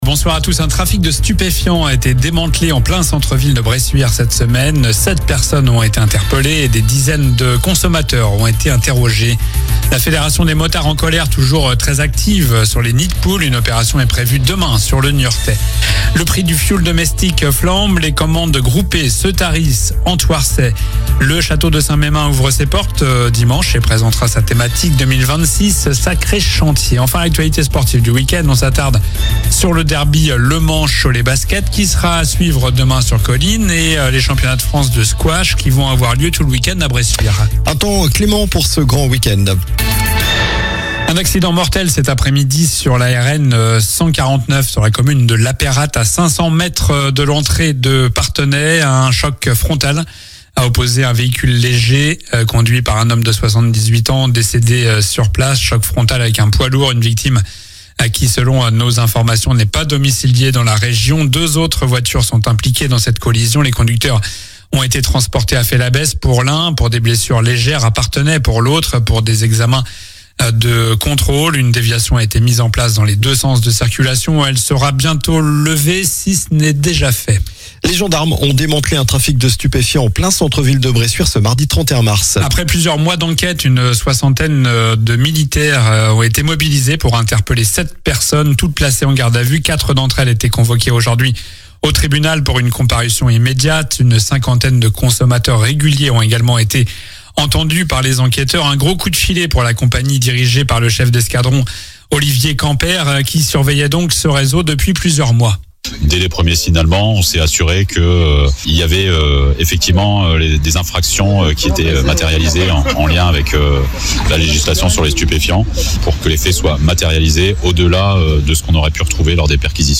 Journal du vendredi 31 mars (soir)